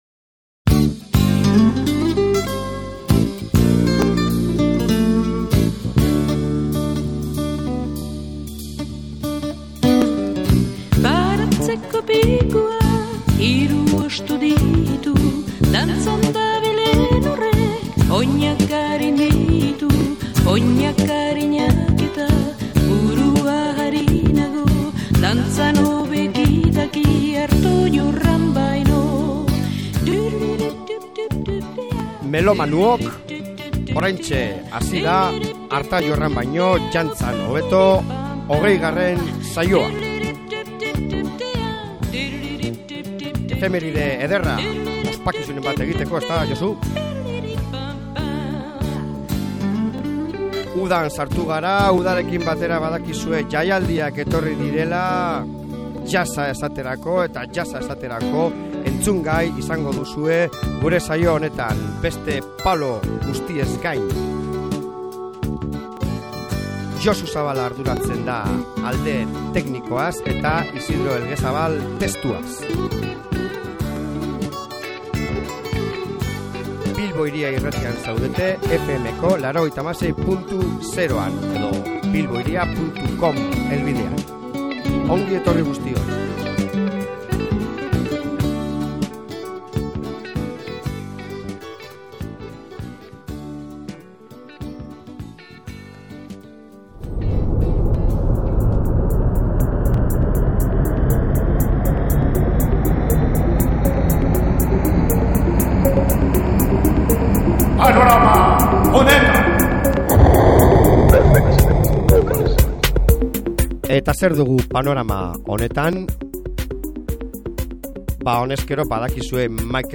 jazz pittin bat